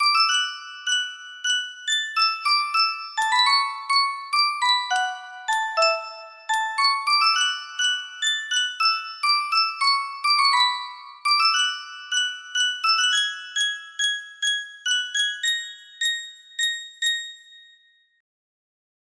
Worried Halflings music box melody
Full range 60